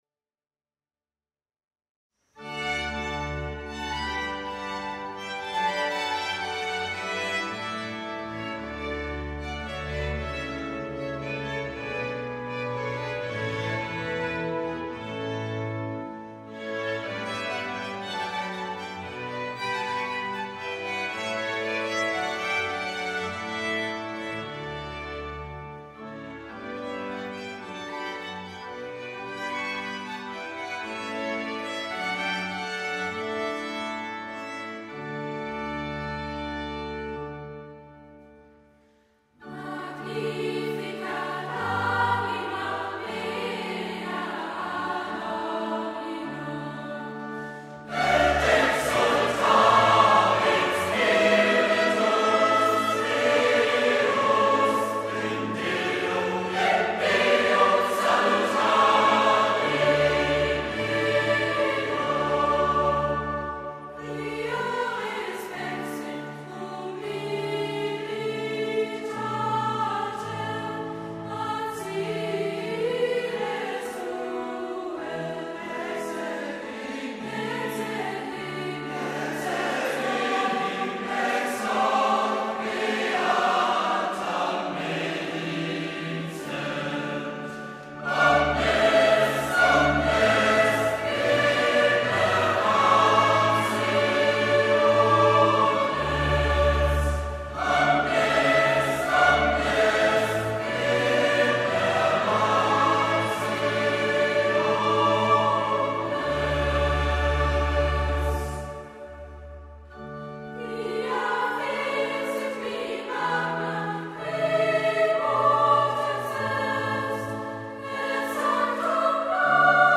Musik-Workshop & CD-Produktion 2011